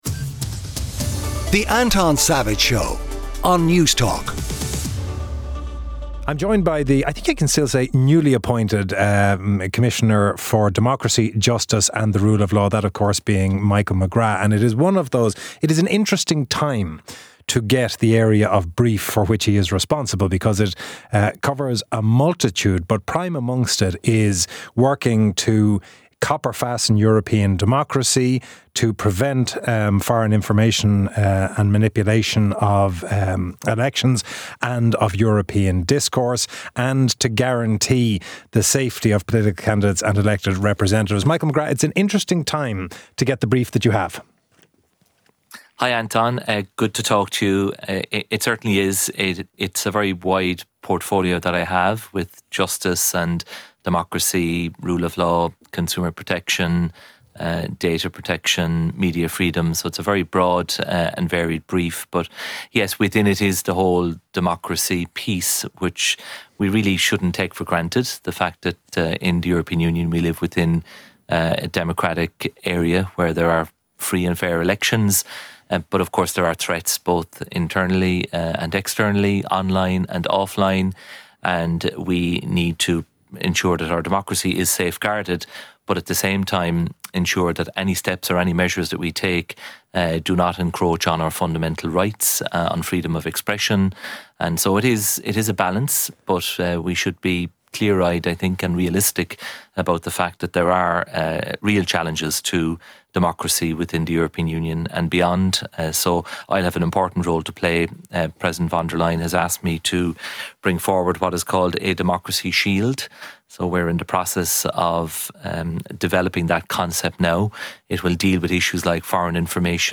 Michael McGrath, Commissioner for Democracy, Justice, the Rule of Law, and Consumer Protection, joins Anton to explore the breadth of his brief - that being, the need to copper fasten European democracy, prevent foreign intervention & manipulation of elections, guaranteeing the safety of political candidates and elected representatives, and the growing threat of Russian military advances.